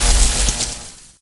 jess_hit_01.ogg